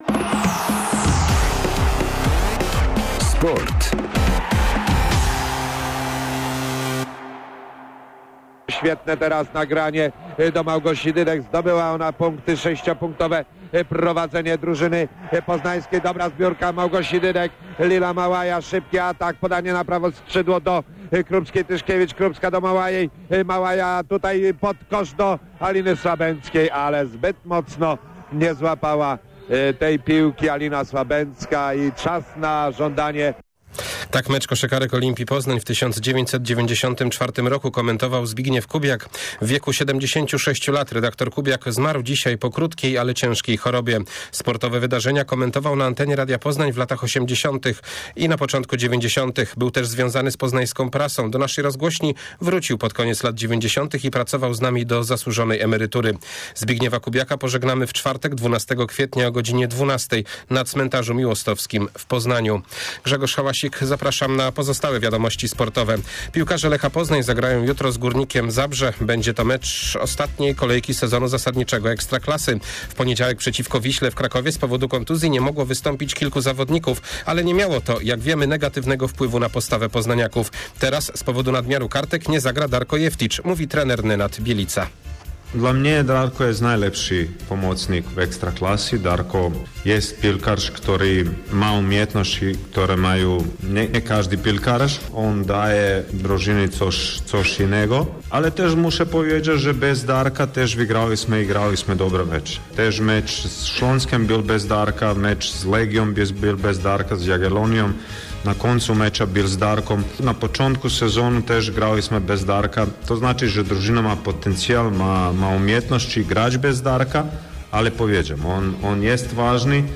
06.04 serwis sportowy godz. 19:05